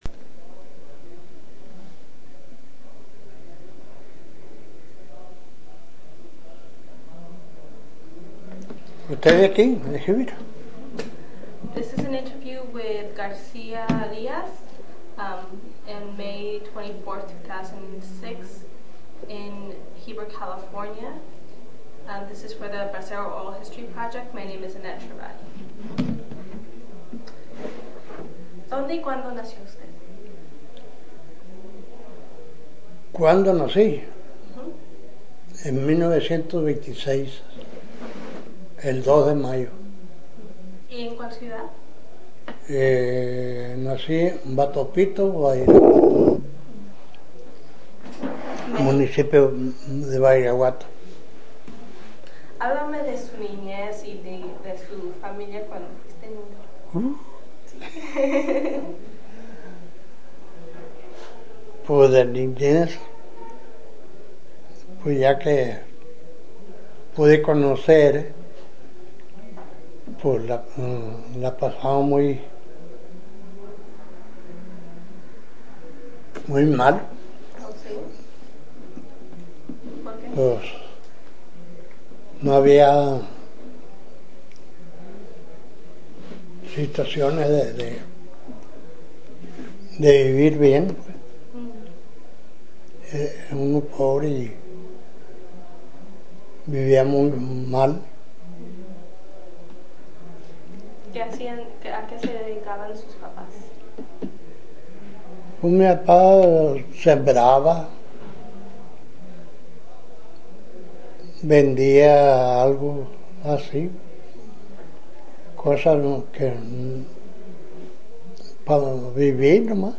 Location Heber, CA Original Format Mini disc